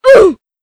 Roblox-Death.wav